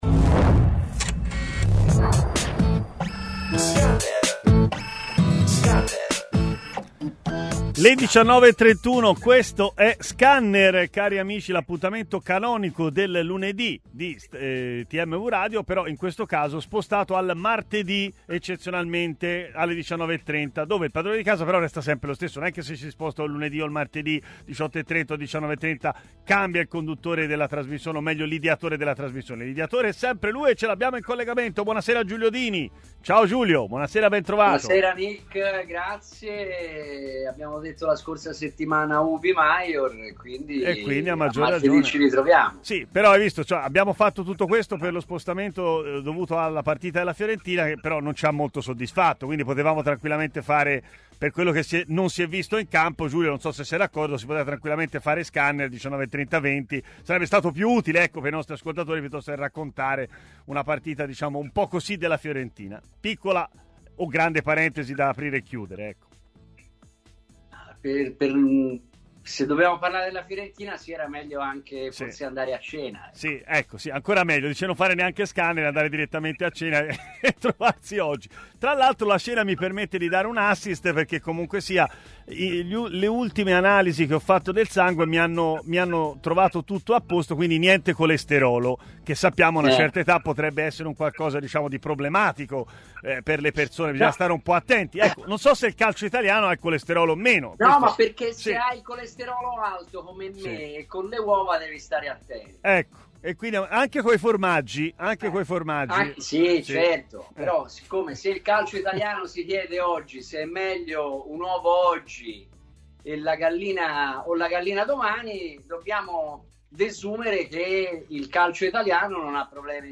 Ospite telefonico